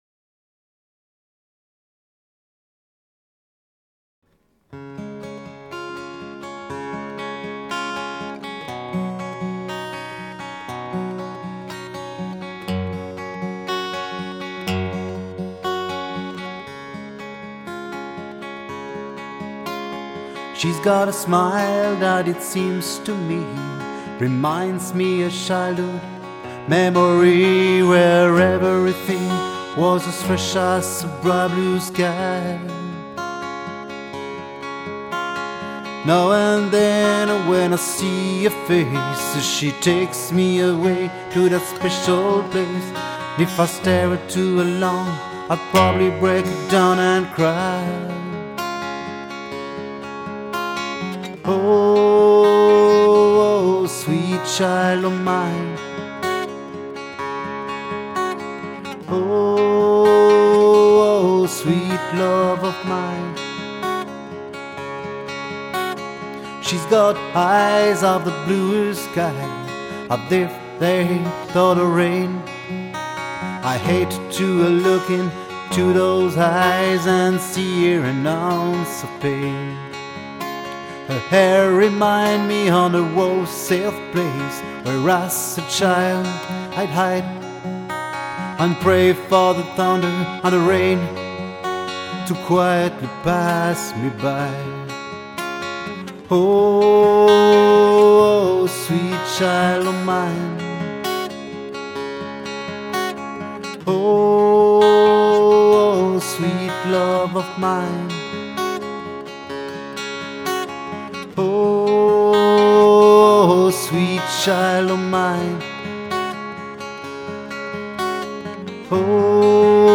il s'agit de 2 cover acoustic faits ce week-end,
(guitare et voix 'one shot')
oui, dans ce contexte, je joue en même temps que je chante,